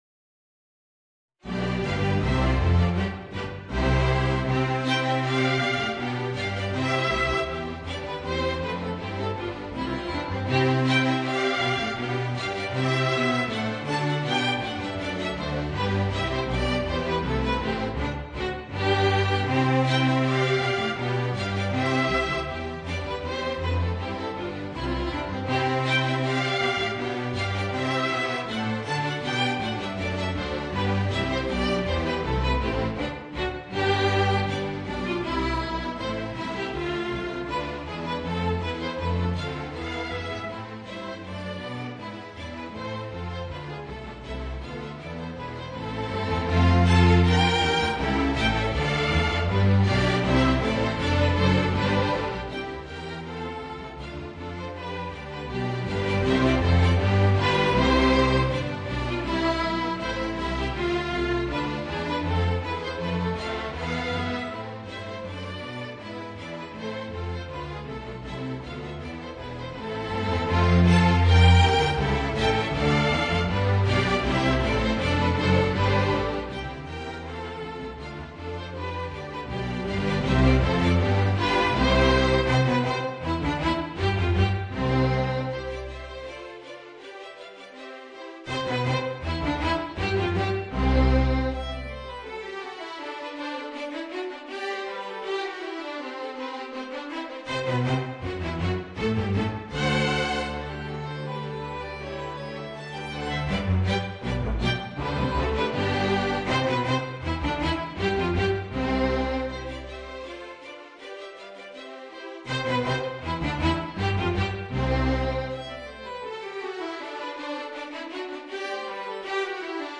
Voicing: Clarinet and String Orchestra